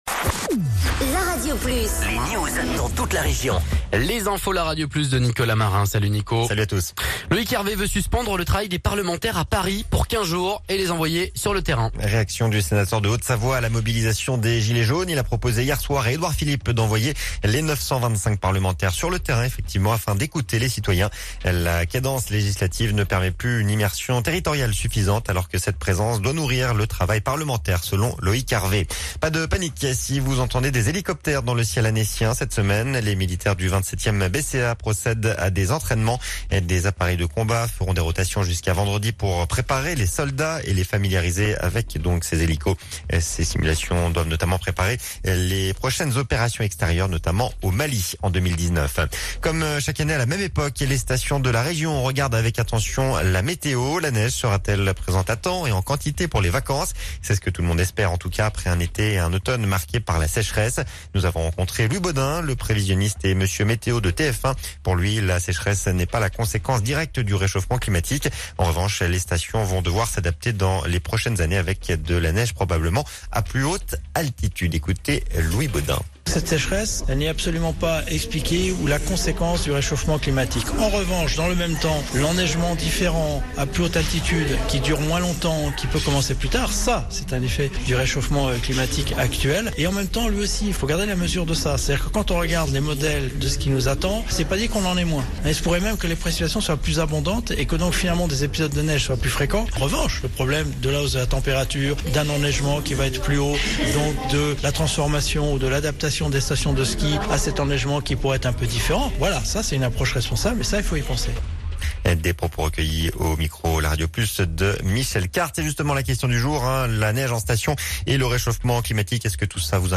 04.12.17 Flash Info 7H
Interview du nouveau maire de Cluses, Jean-Philippe Mas